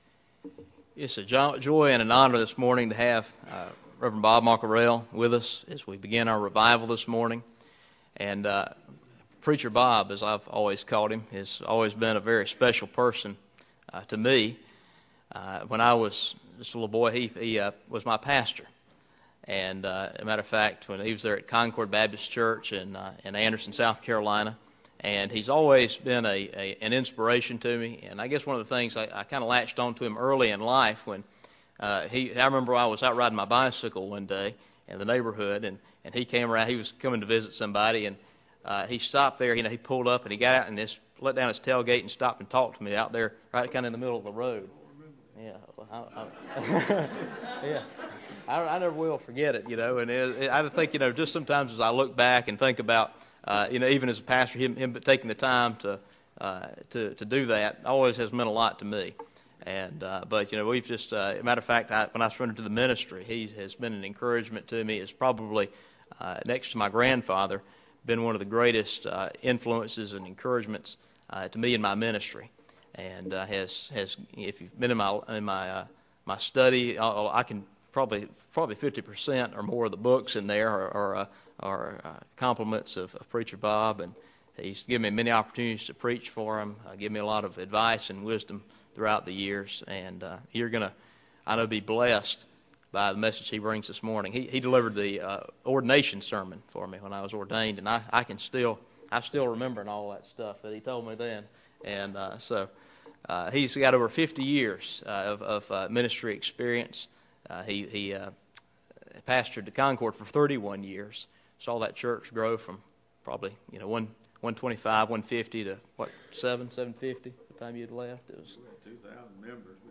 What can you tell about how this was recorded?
Fall 2015 Revival Sunday Morning